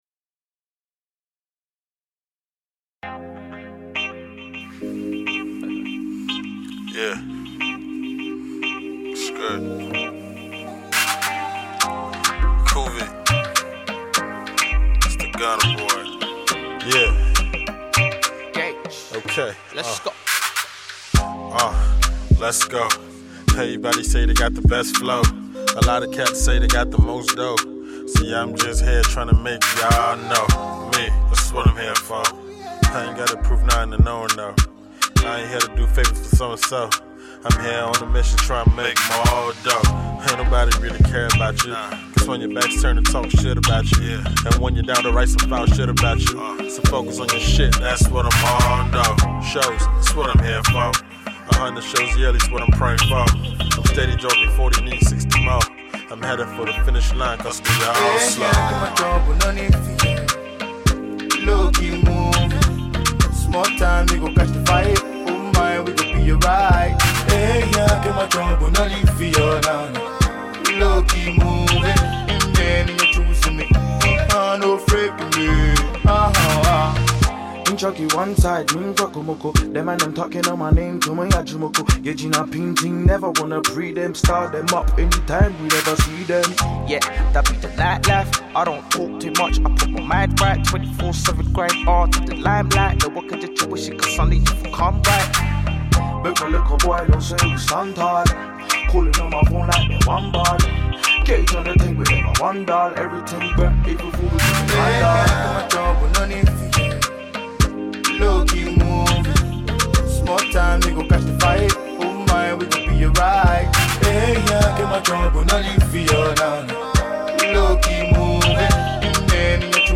Ghana MusicMusic
Ghanaian heavyweight rapper
midtempo song